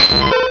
Cri de Ptitard dans Pokémon Rubis et Saphir.
Cri_0060_RS.ogg